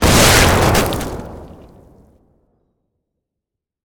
combat / weapons / rocket / flesh2.ogg
flesh2.ogg